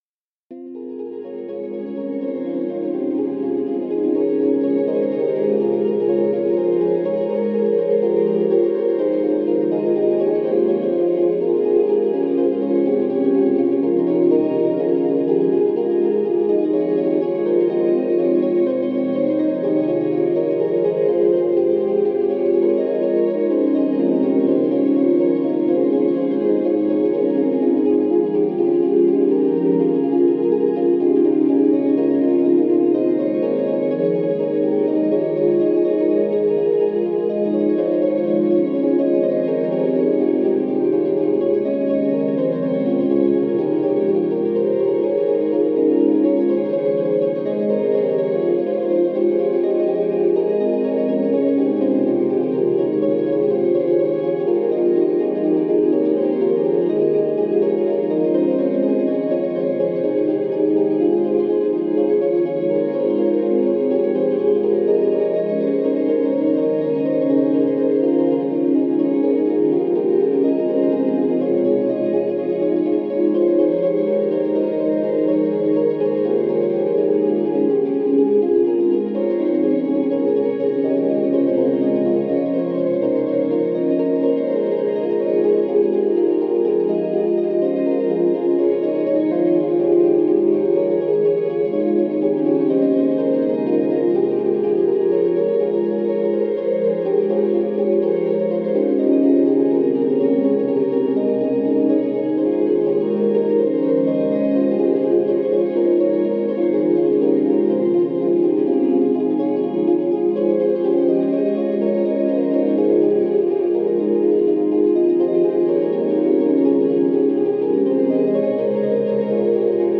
Atmospheric ambient/electronic music.
energetic, sequence-driven minimalism